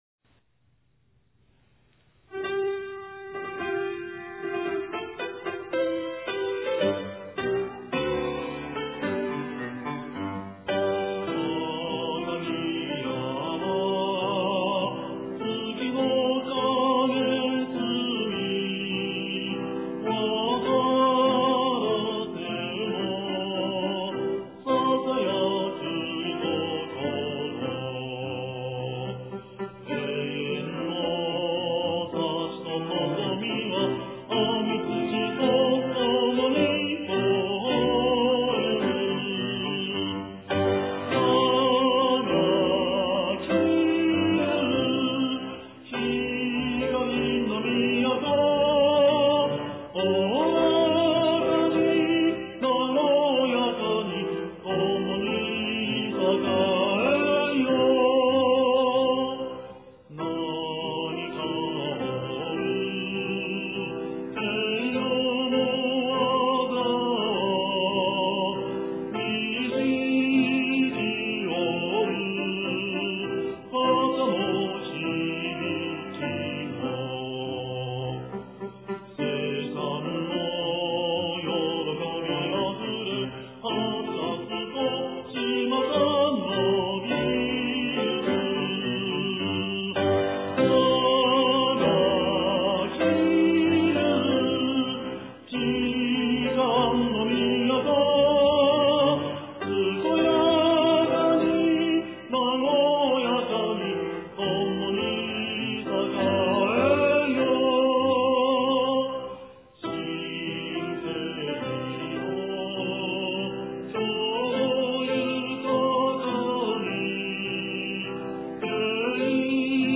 男性独唱